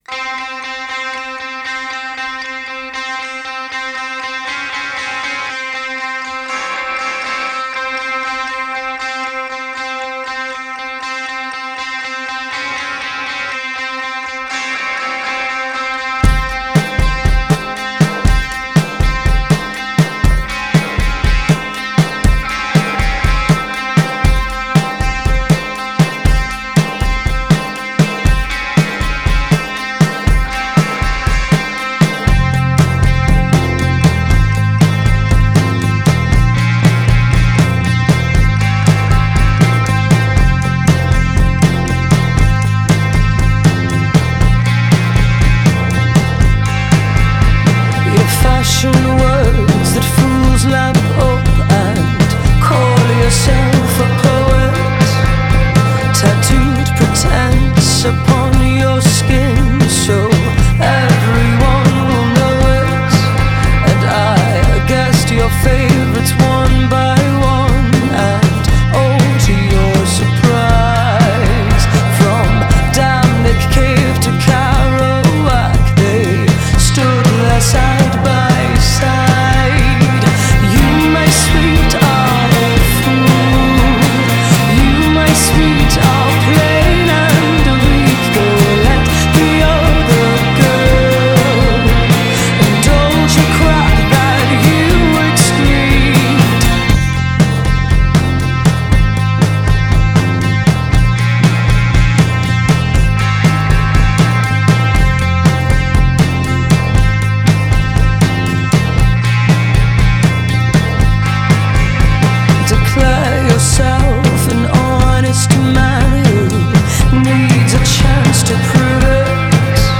Жанр: Alternative.